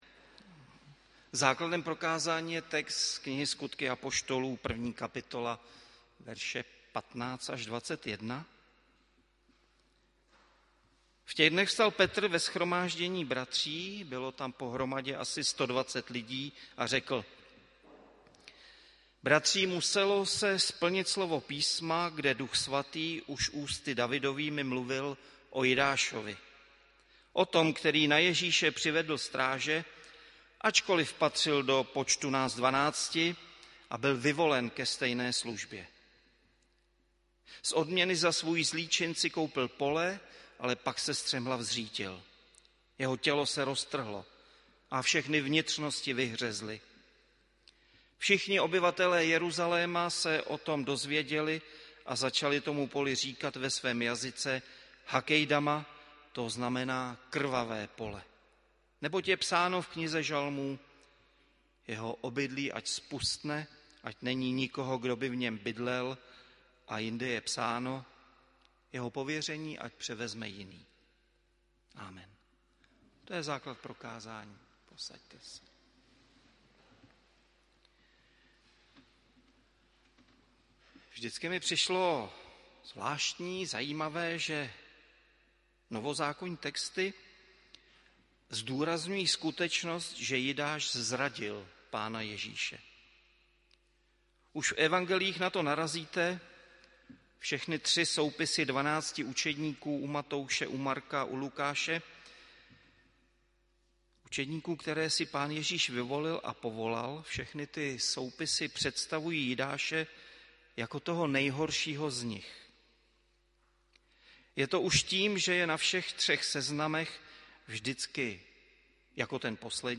administrátor sboru. audio kázání zde sborová ohlášení zde
Desátá neděle po sv. Trojici 21. srpna 2022 AD